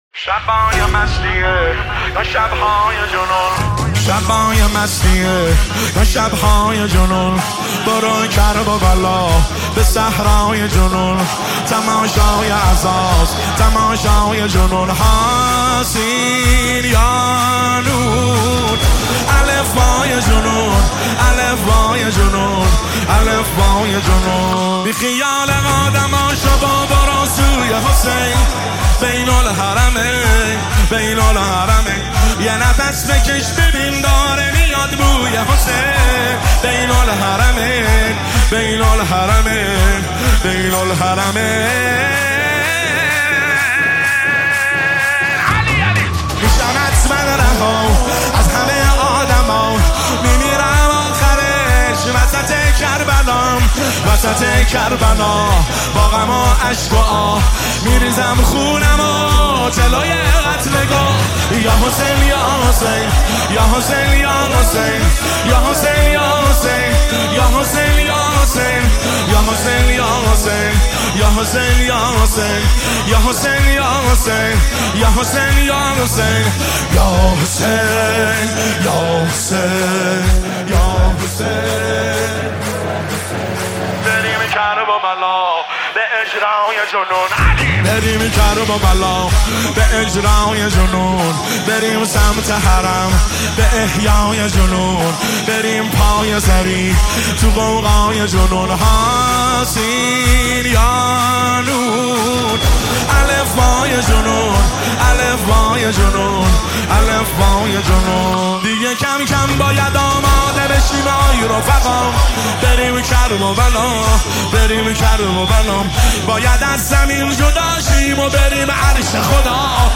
اثری عاشورایی و عاطفی است
موسیقی حماسی